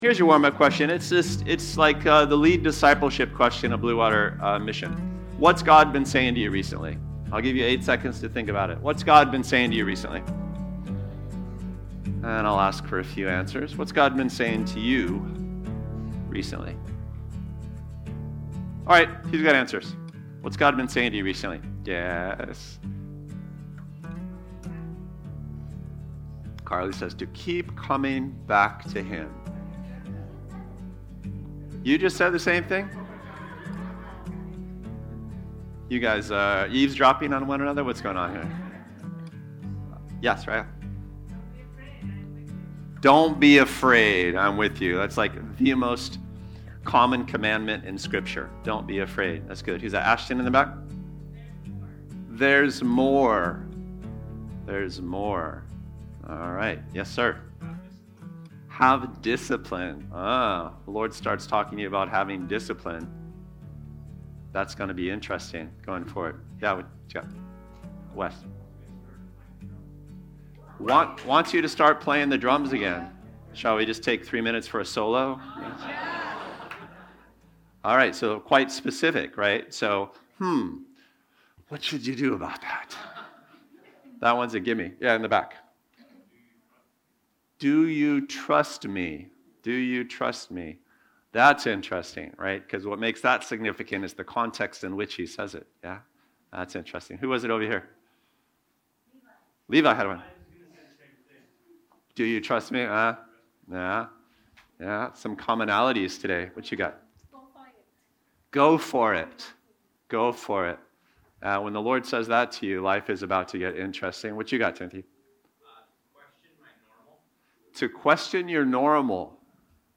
Recorded Remotely.